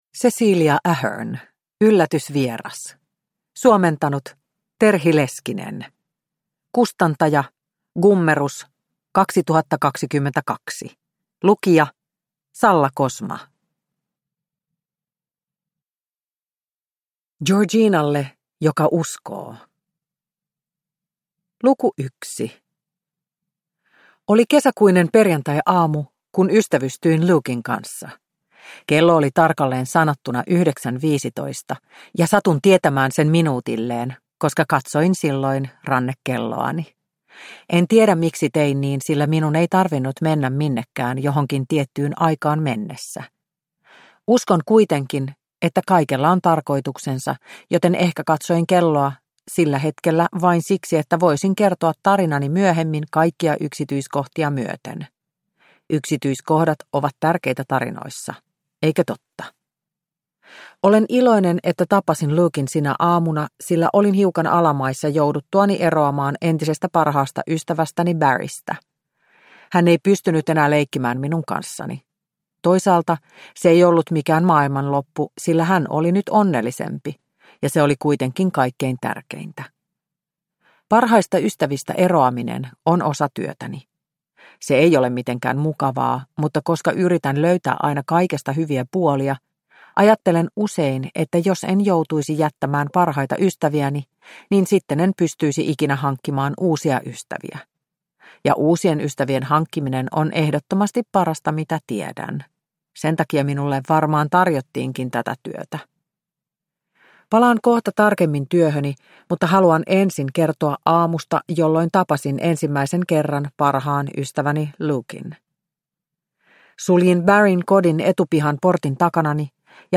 Yllätysvieras – Ljudbok